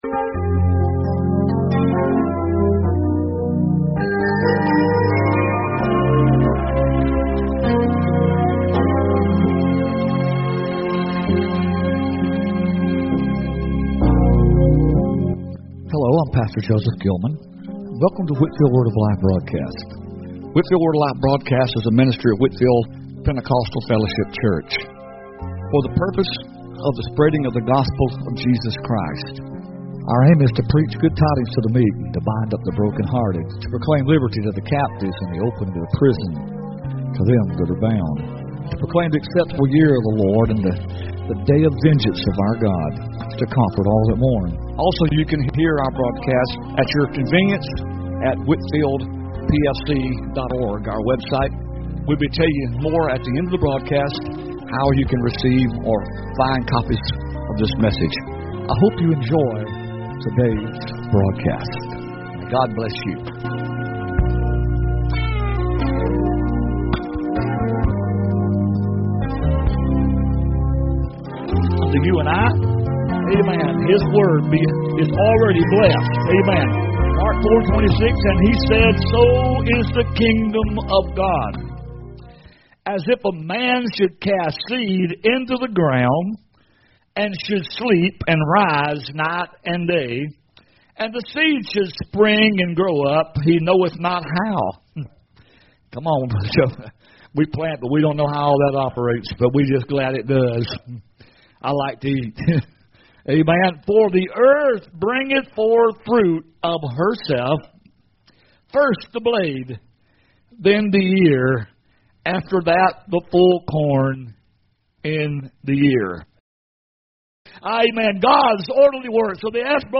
Word of Life Broadcast 02-15-26 – Whitfield PFC